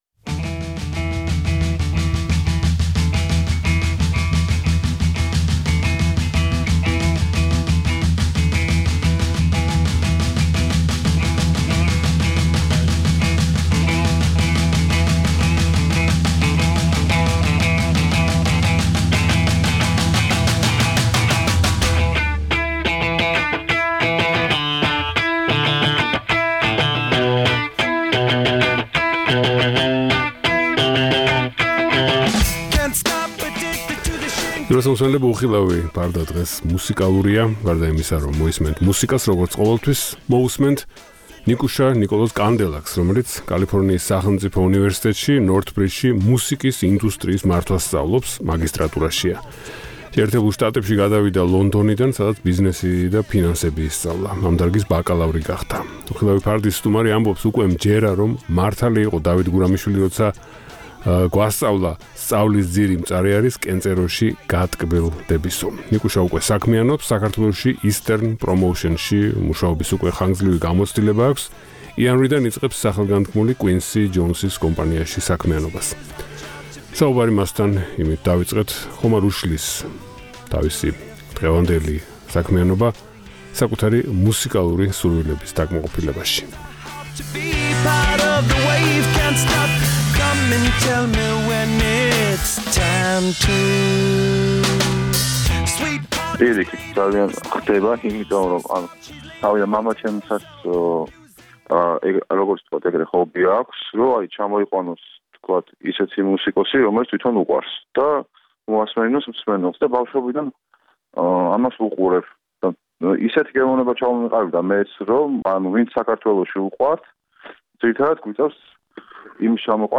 "უხილავი ფარდა" დღეს ერთობ მუსიკალურია. მოისმენთ მუსიკას